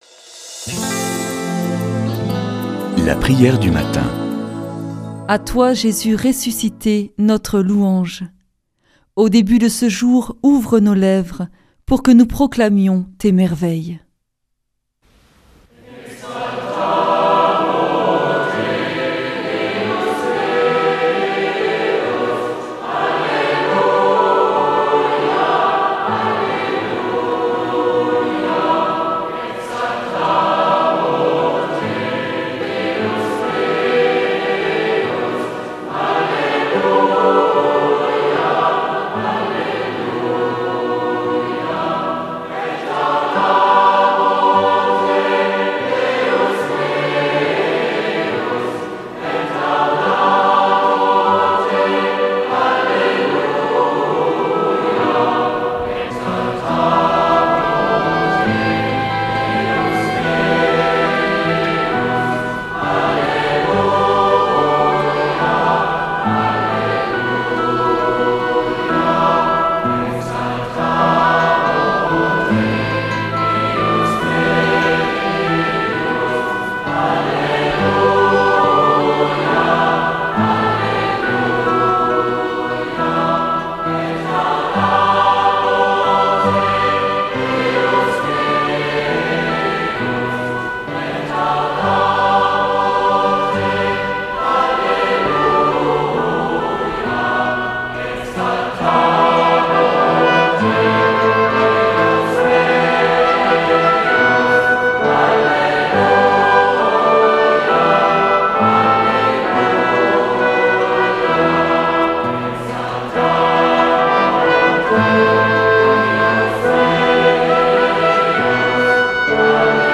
Une émission présentée par Groupes de prière